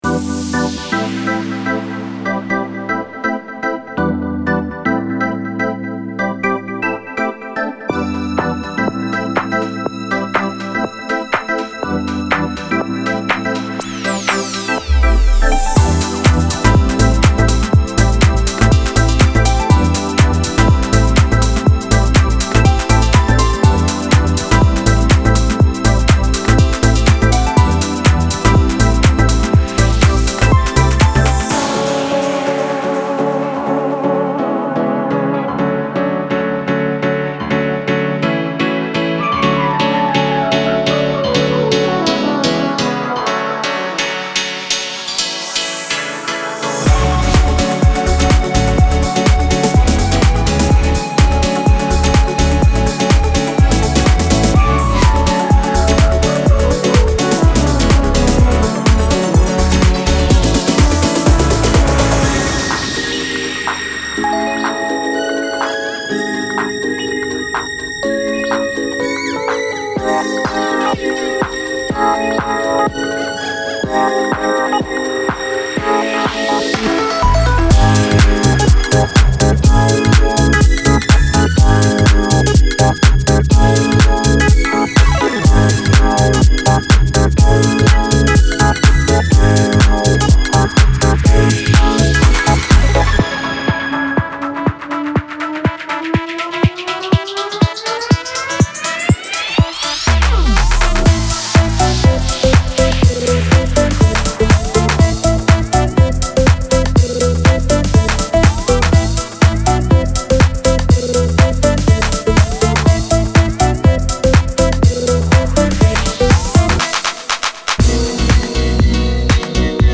Genre:Classic House
デモサウンドはコチラ↓
120, 122, 124, 125, 126, 128, 130 BPM
241 Wav Loops (Basses, Synths, Drums, Fx, Guitars)